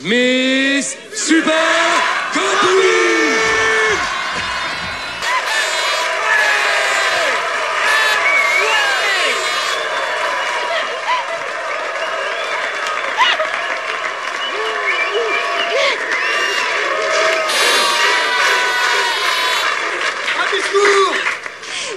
AMBIANCES SONORES DE FOULES
Soirée arrosée